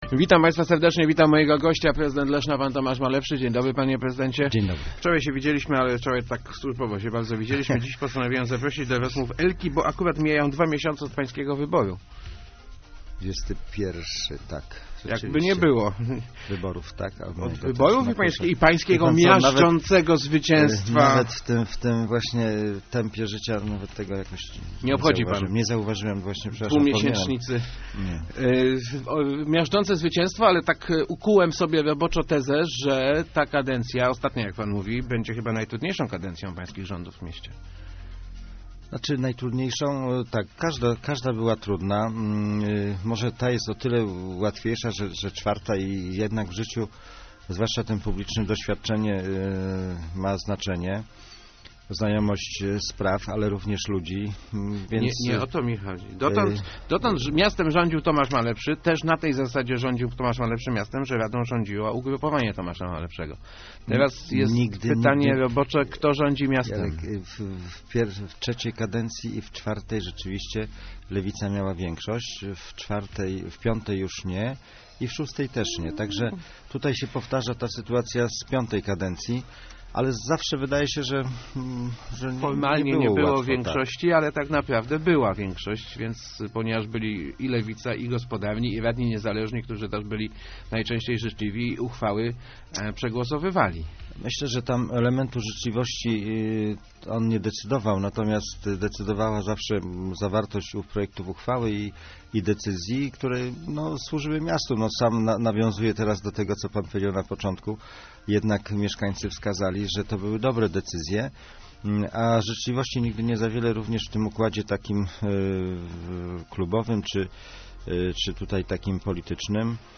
Marek Wein jeszcze zrobi wiele dla leszczyńskiej oświaty - mówił w Rozmowach Elki Tomasz Malepszy. Prezydent Leszna nie chciał jednak zdradzić, jakie stanowisko powierzy byłemu dyrektorowi I LO, obecnie radnemu Gospodarnych dla Leszna.